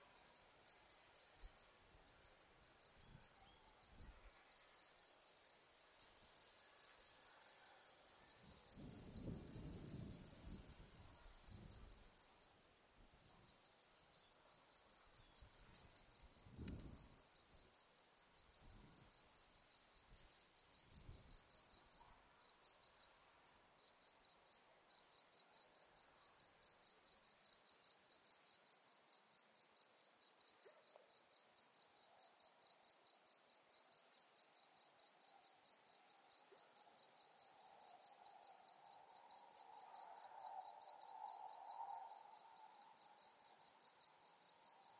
蟋蟀
描述：蟋蟀在一个春天晚上
标签： 性质 昆虫 蟋蟀 啁啾
声道立体声